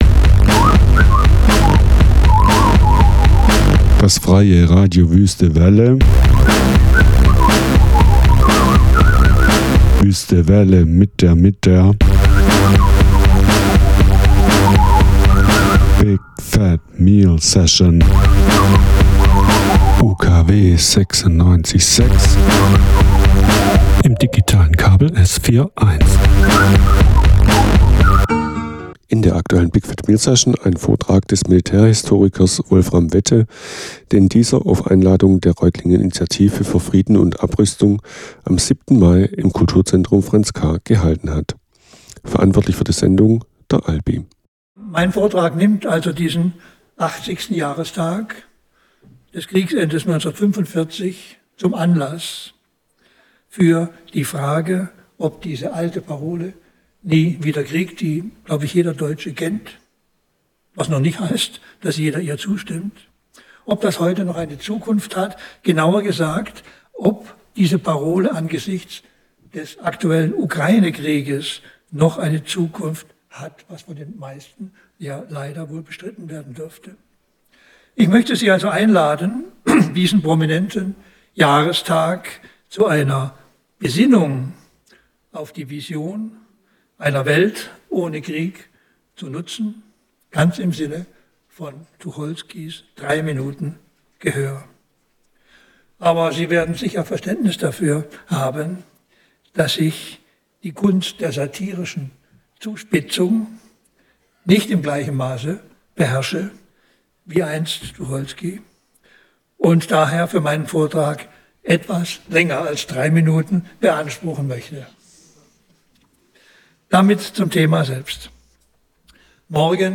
Ein Vortrag des Militärhistorikers Prof. Dr. Wolfram Wette
Prof. Dr. Wolfram Wette beleuchtet in seinem Vortrag, den er am Abend vor dem Tag der Befreiung vom Nationalsozialismus im Reutlinger franz.K gehalten hat, wie es um die Gültigkeit der Parole bestellt ist.